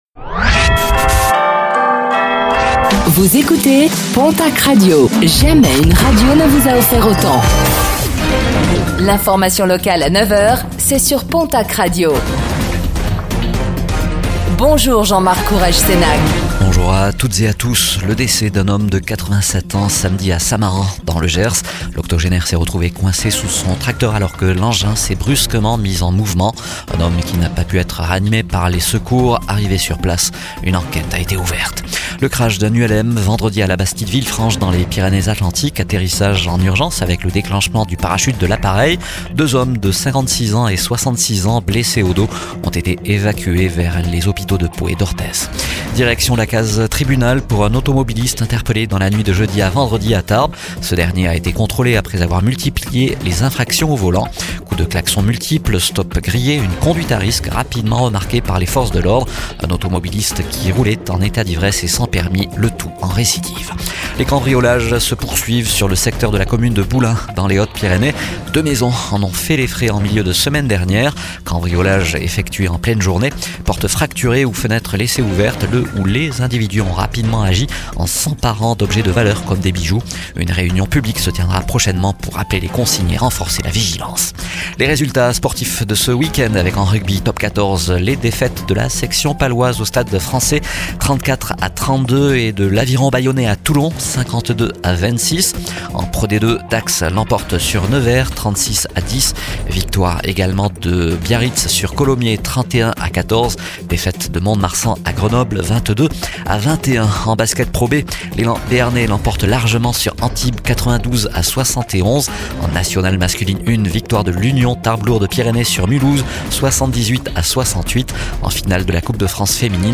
Réécoutez le flash d'information locale de ce lundi 27 avril 2026